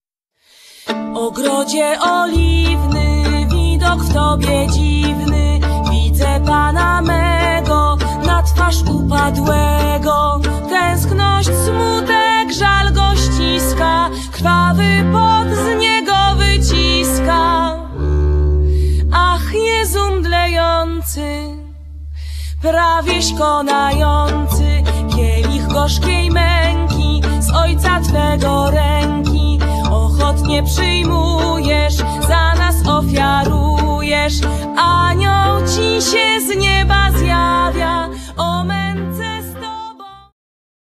śpiew, skrzypce
kontrabas, fisharmonia
altówka, fisharmonia
altówka, lira korbowa